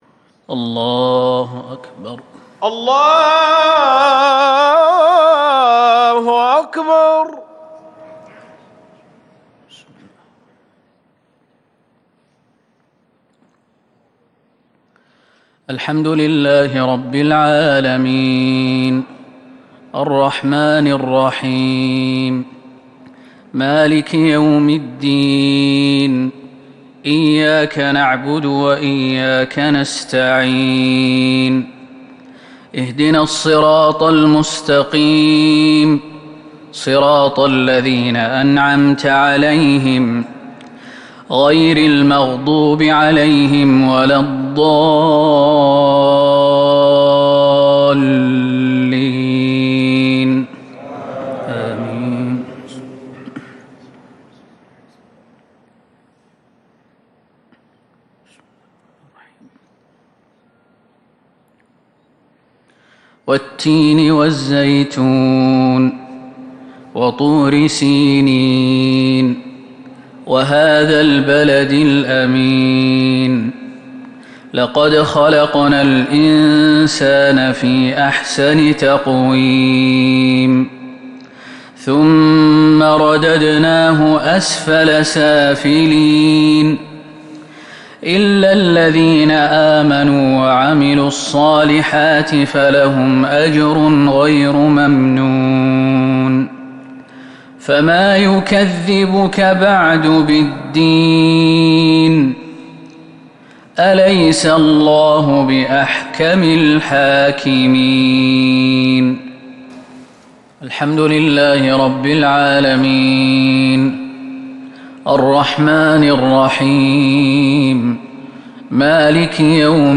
صلاة المغرب من سورتي التين والقدر 8 ربيع الأول 1442ه‍ـ | maghrib prayer from Surat At-tin and Surat Al-Qadr 25/10/2020 > 1442 🕌 > الفروض - تلاوات الحرمين